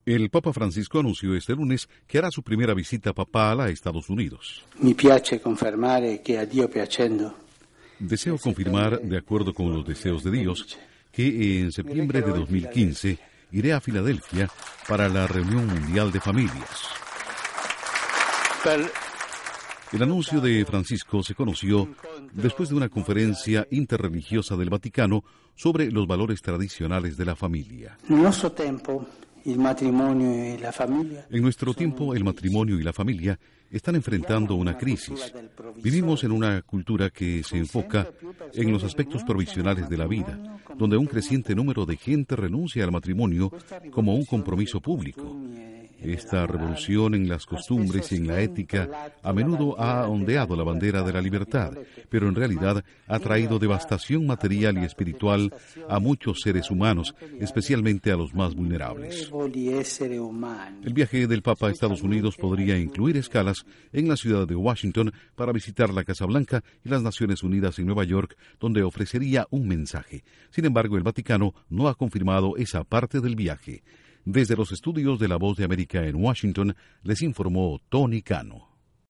Papa Francisco confirma que viajará a Estados Unidos. Informa desde los estudios de la Voz de América en Washington